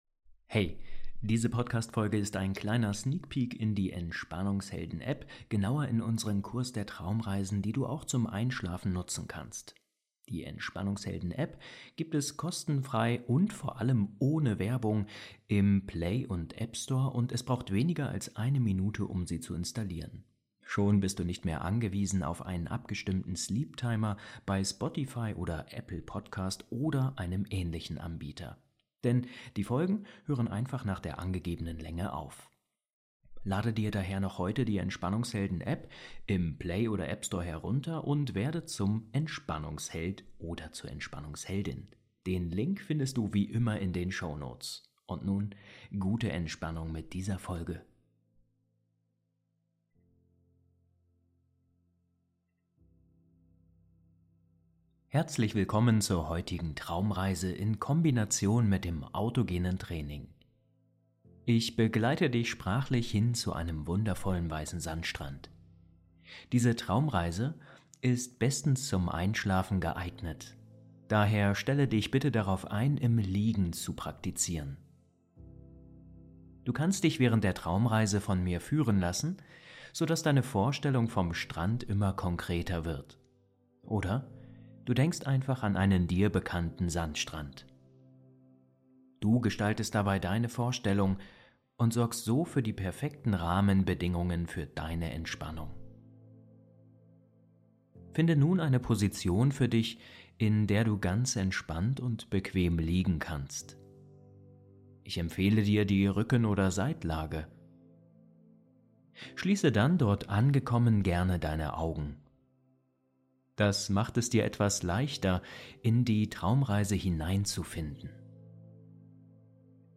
Diese geführte Traumreise nimmt dich mit an einen traumhaften Strand, wo du durch die Kombination von Fantasiereise und Elementen des Autogenen Trainings in tiefe Entspannung gelangst. Die sanften Anleitungen helfen dir, Körper und Geist zu beruhigen und den Alltag loszulassen.